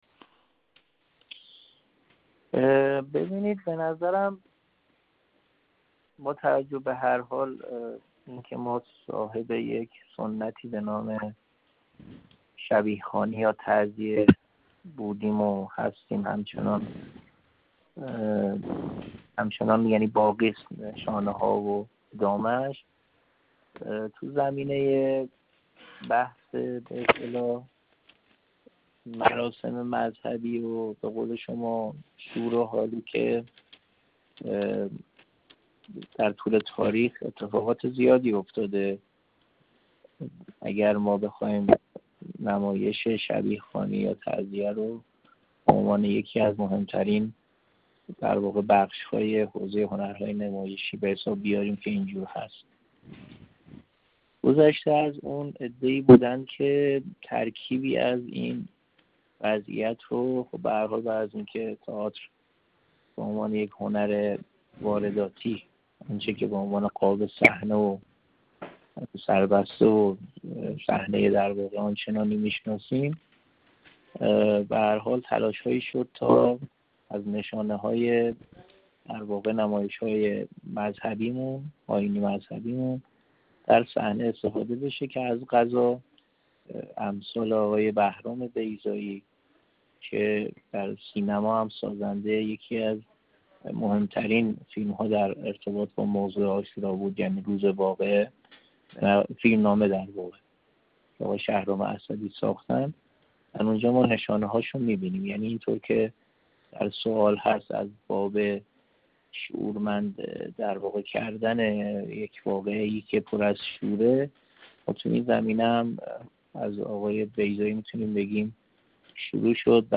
ایکنا با این هنرمند پیرامون توجه به عاشورا در برنامه‌های نمایشی سیما گفت‌وگویی انجام داده است.